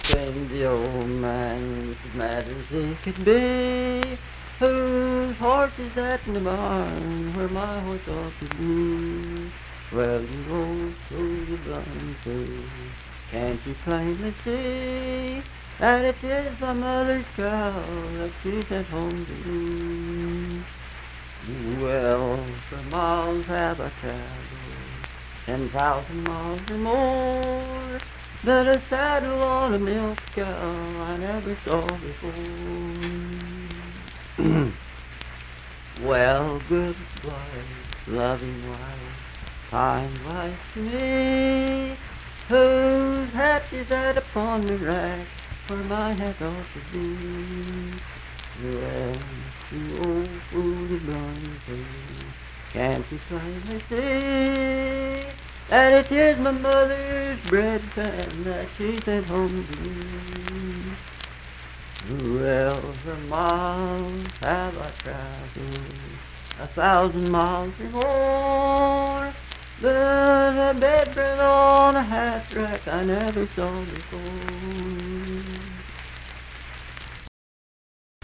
Unaccompanied vocal music
Voice (sung)